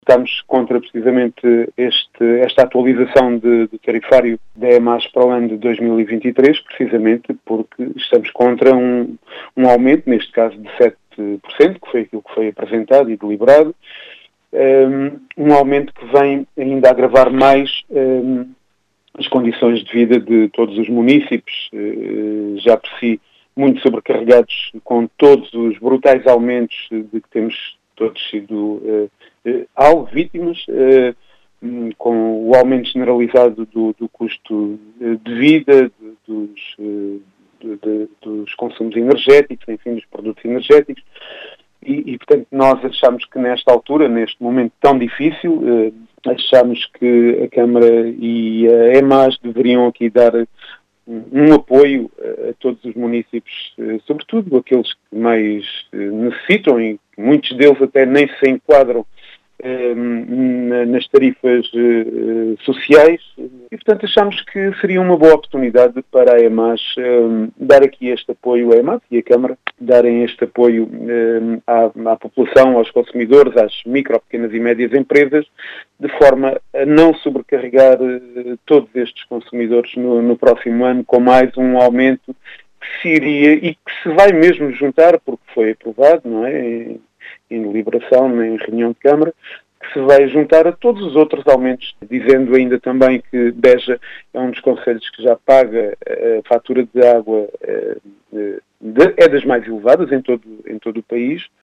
As explicações são de Rui Eugénio, vereador da CDU na Câmara Municipal de Beja, que contesta esta actualização do tarifário da Empresa Municipal de Água e Saneamento de Beja.